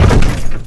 velox / Assets / sounds / suspension / compress_heavy_1.wav
compress_heavy_1.wav